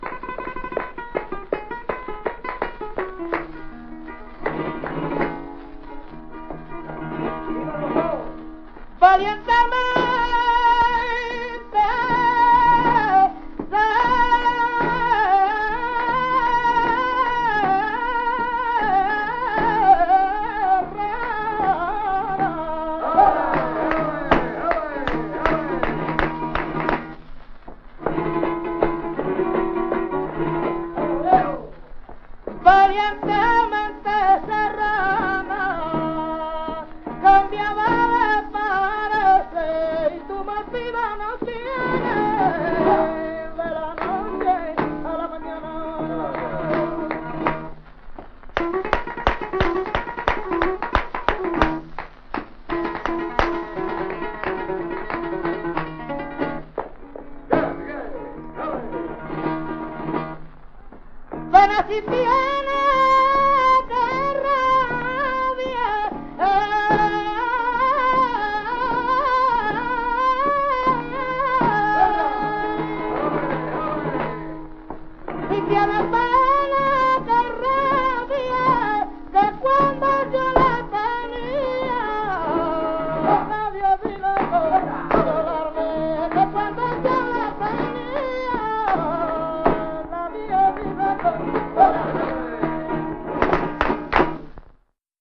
Bulería por soleá / 8